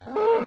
mob / cow / hurt1.ogg
hurt1.ogg